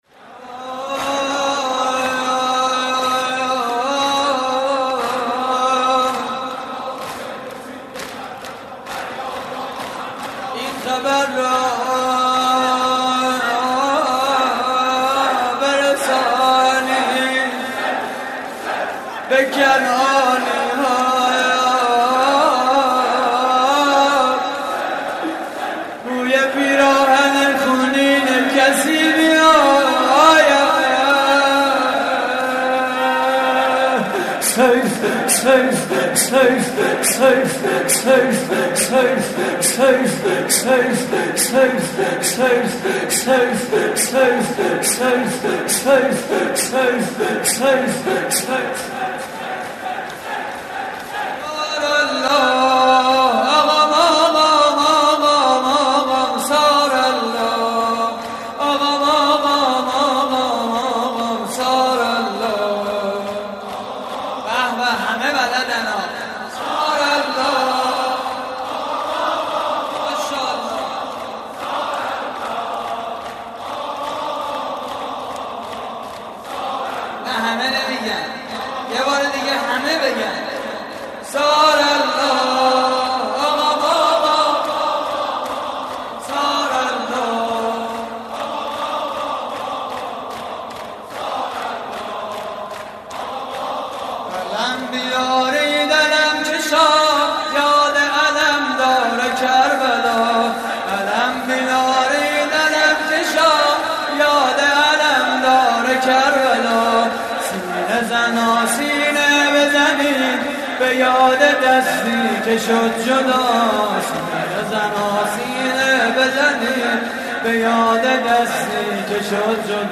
مراسم عزاداری شب دوم ماه محرم / هیئت الزهرا (س) – دانشگاه صنعتی شریف؛ 26 آبان 1391
صوت مراسم:
شور: علم بیارید علم کشا؛ پخش آنلاین |